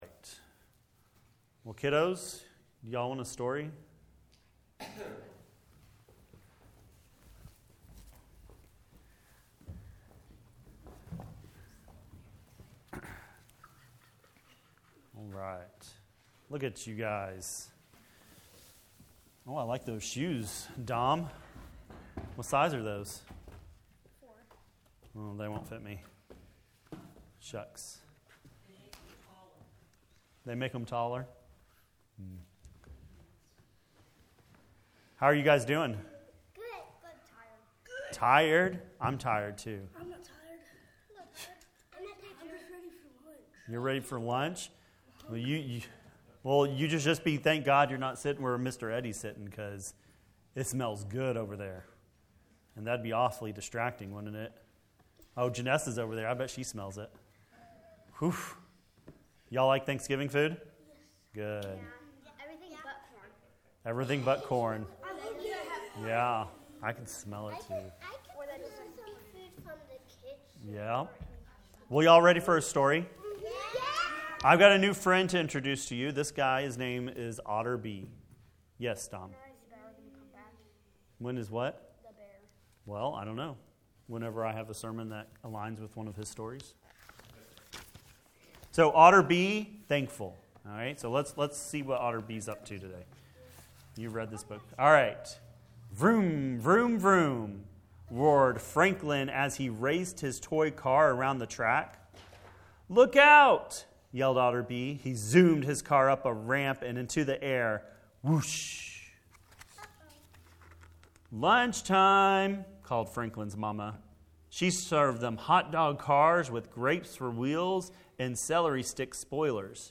wp-content/uploads/2024/11/Thankful.mp3 A sermon on thankfulness.